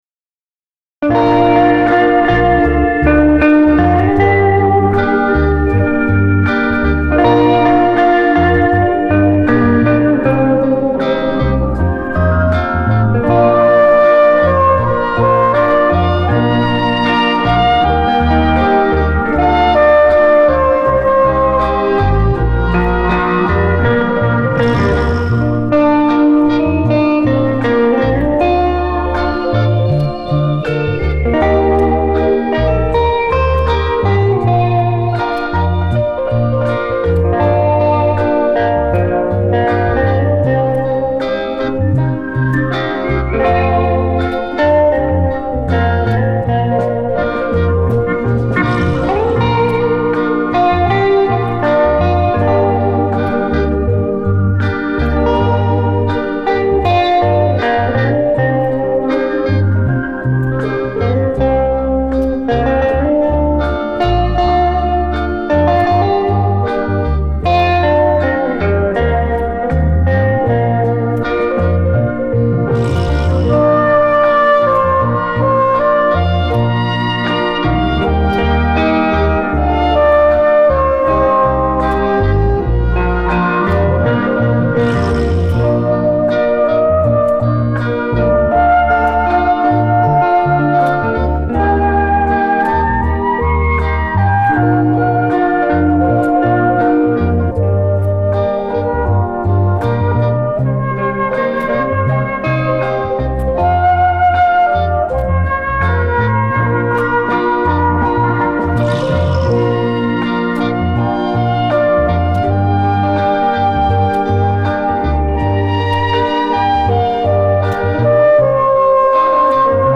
电子琴
吉他
萨克斯风